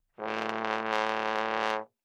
Download Trombone sound effect for free.
Trombone